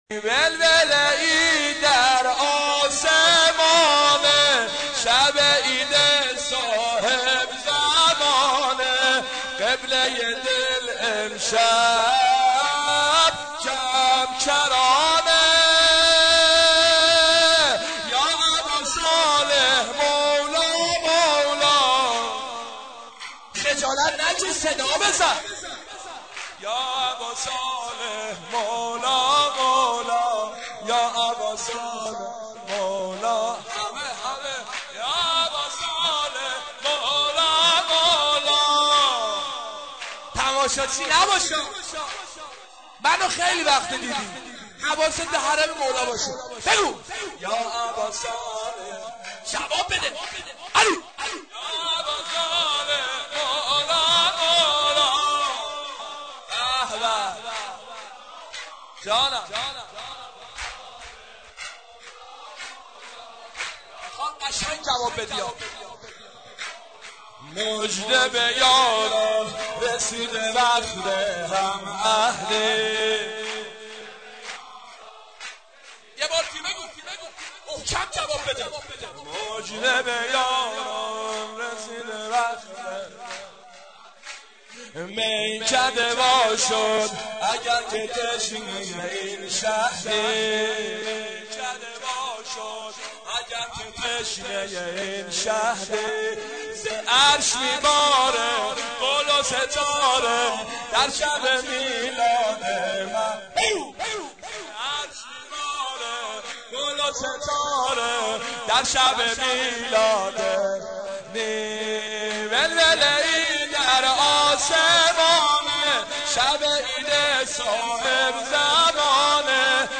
عنوان : سبک میلاد امام زمان